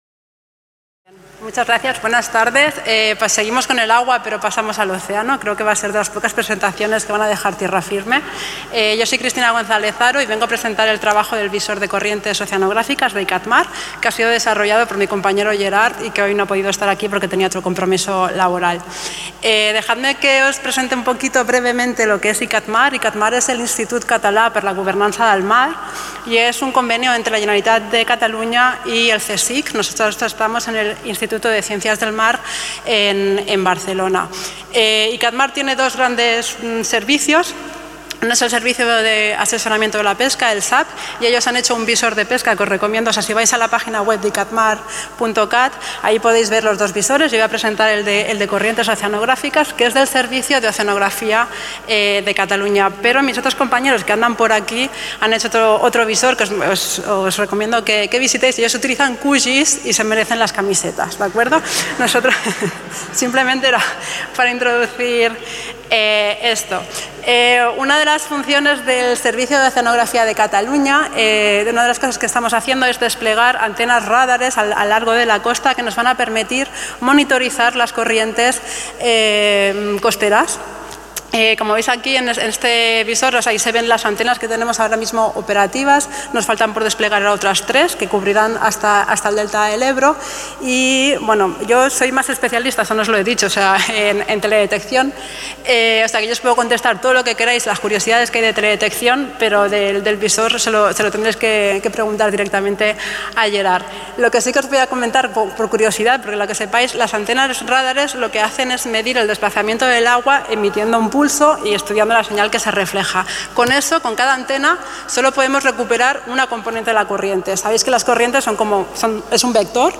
Xerrada